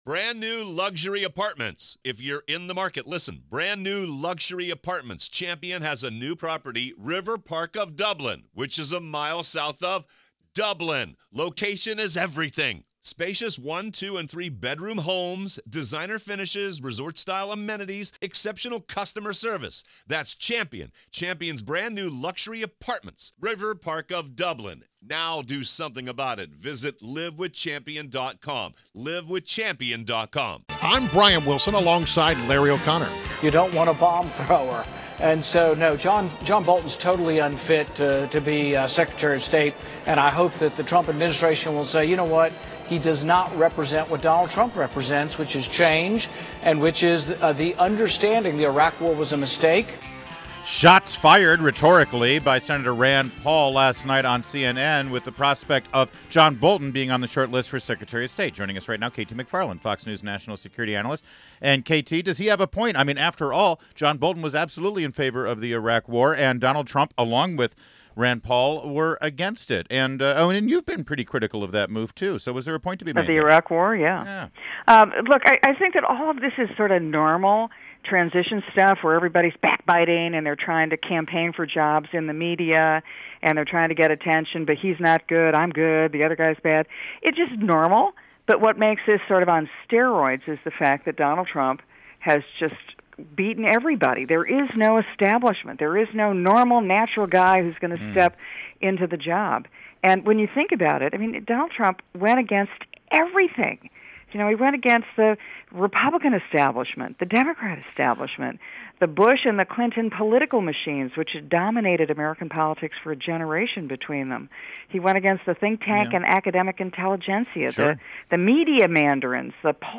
INTERVIEW -- KT MCFARLAND - Fox News National Security Analyst